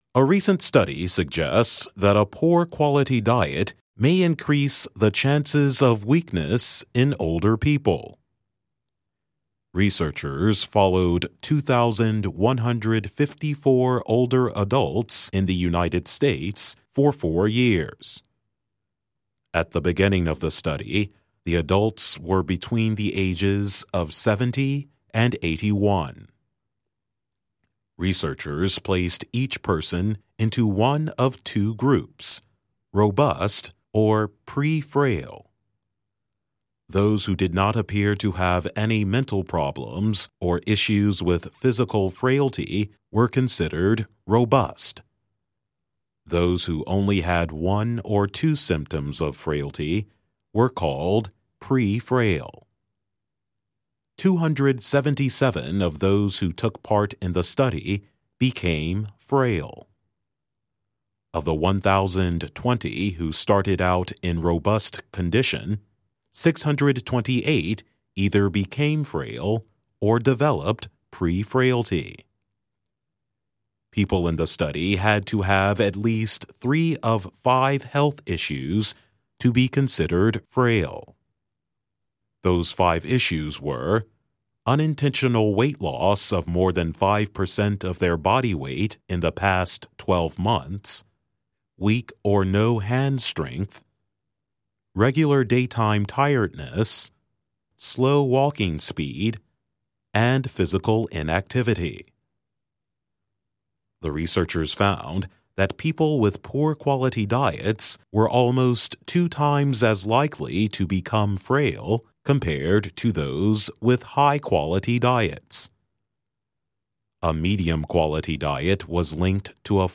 慢速英语:饮食质量差与老年人虚弱有关